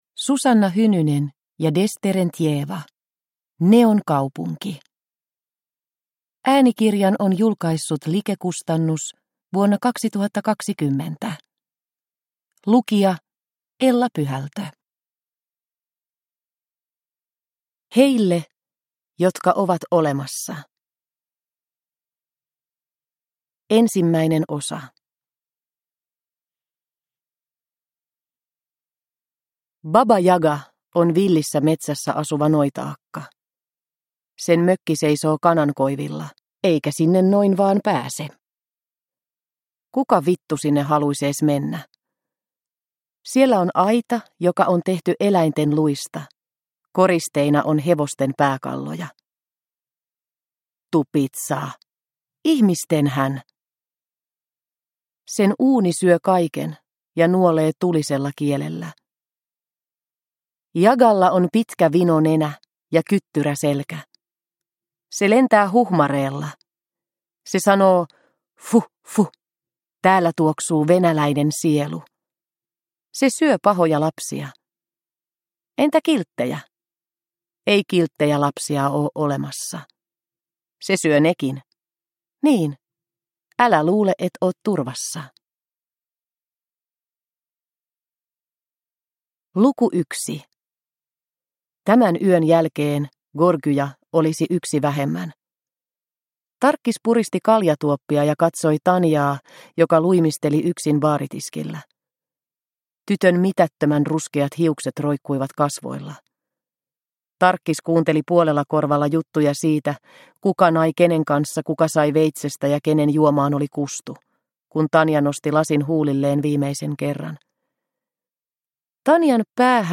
Neonkaupunki – Ljudbok – Laddas ner